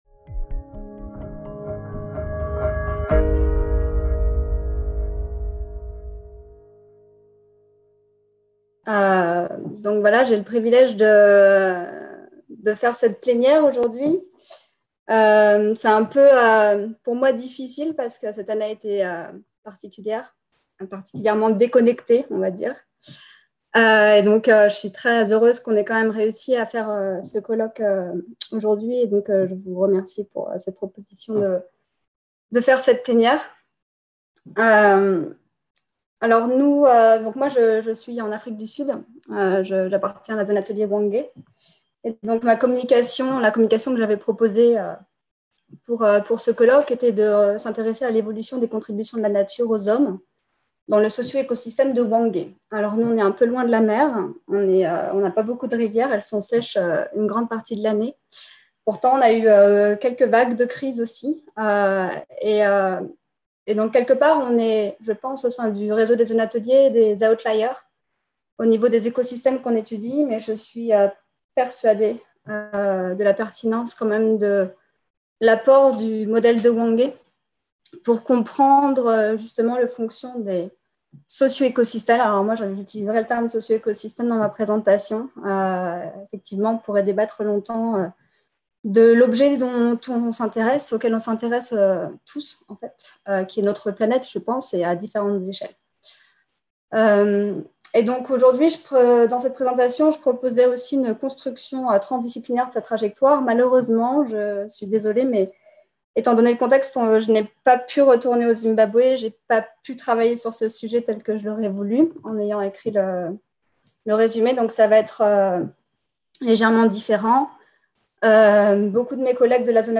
Conférence plénière - Evolution des contributions de la nature aux hommes dans le socio-écosystème de Hwange : vers une construction transdisciplinaire de sa trajectoire | Canal U